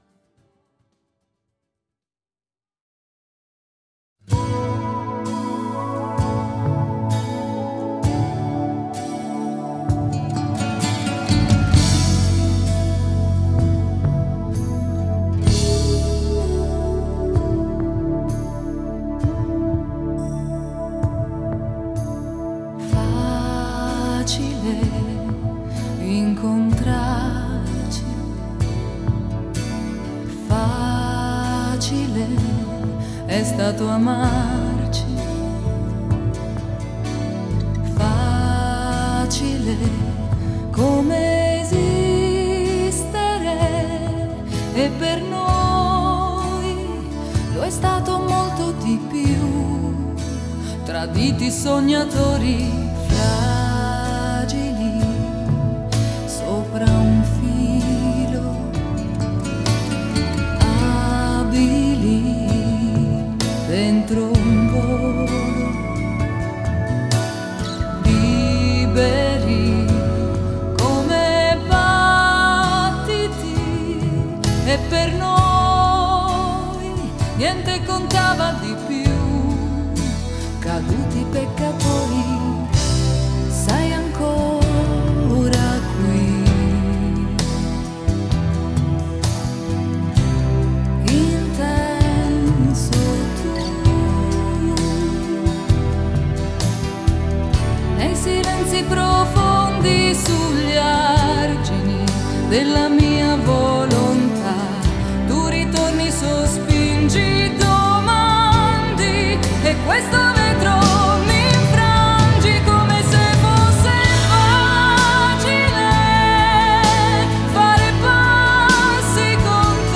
Жанр: Italo Pop